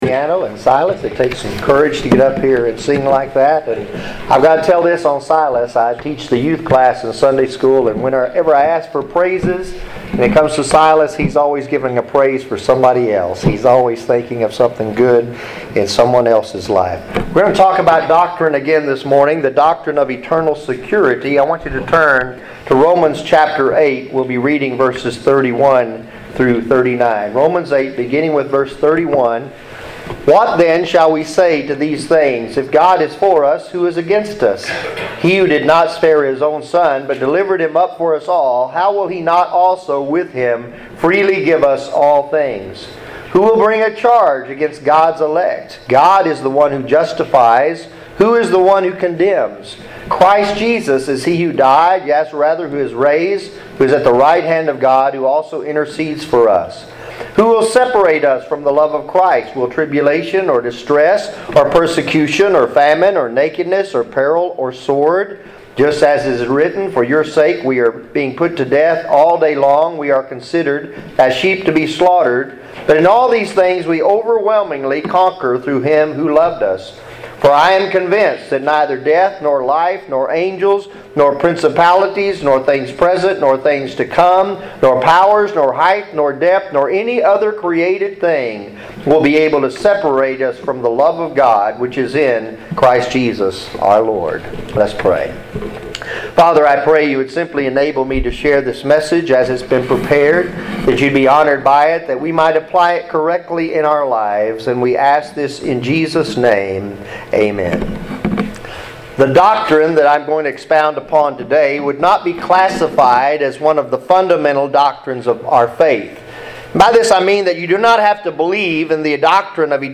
Sermons | Providential Baptist Church